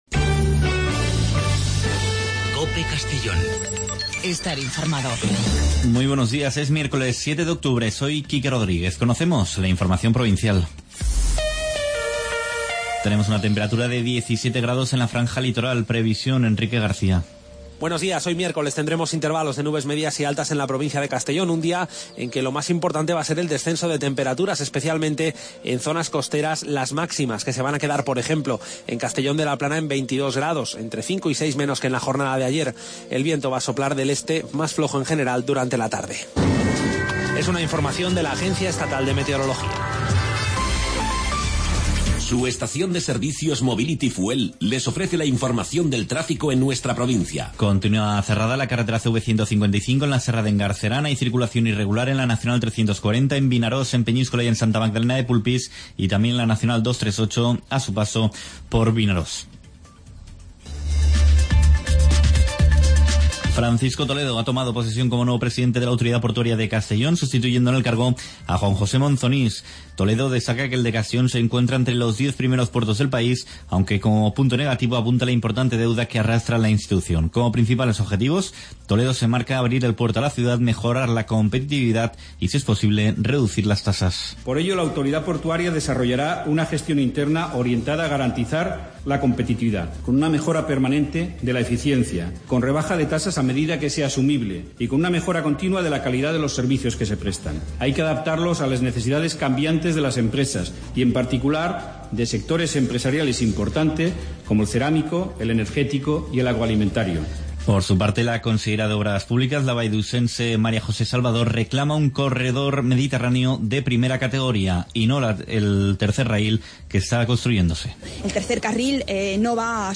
Informativo a nivel provincial con los servicios informativos de COPE en Castellón.